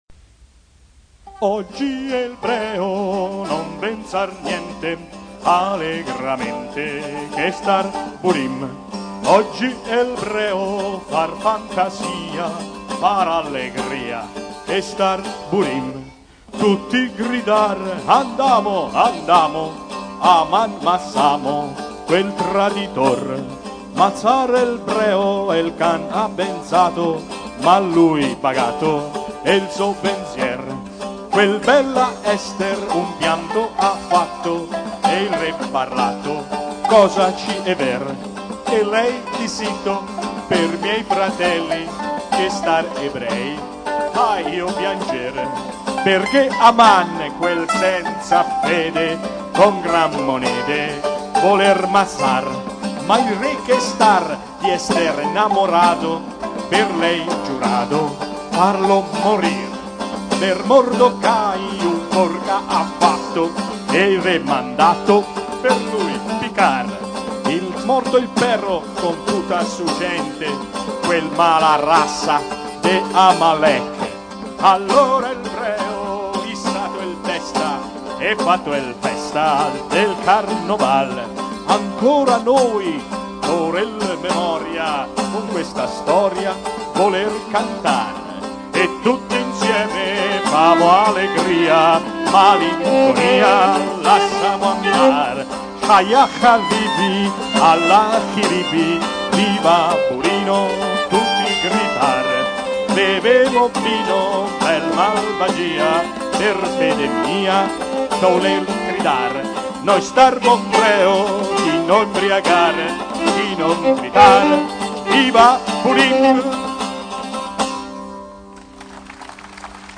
Oggi el breo non bensar niente canto di Purim in lingua franca
piano
registrazione effettuata al Teatro Paisiello di Lecce il 27 gennaio 2004